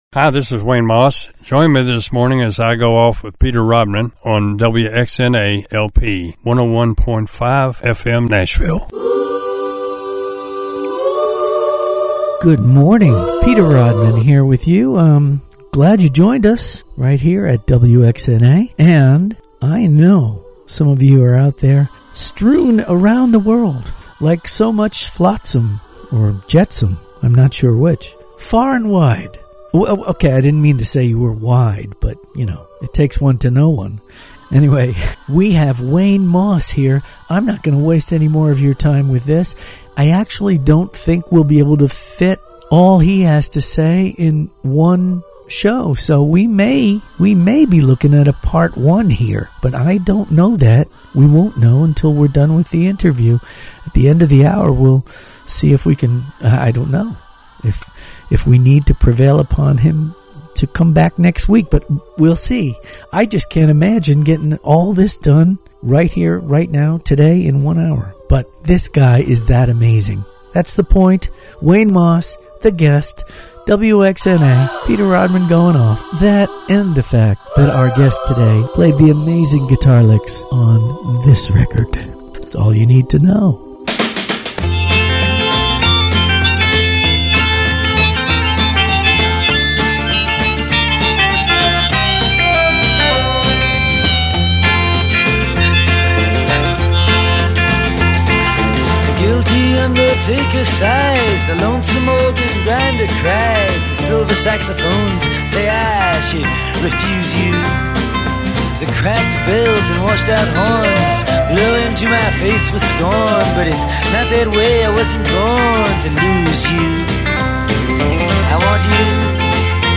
radio inteviews